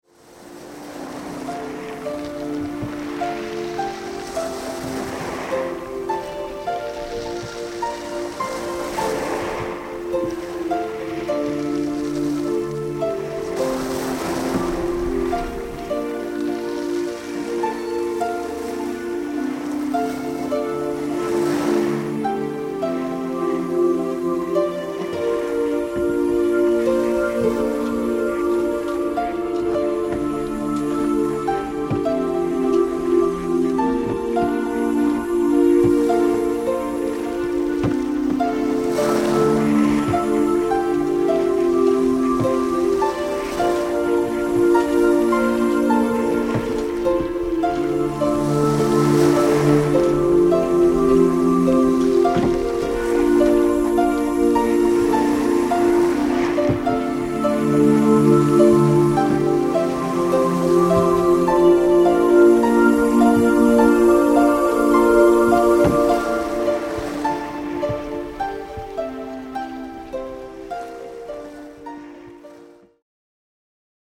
Masten und Boote   04:41 min 0,99 EUR download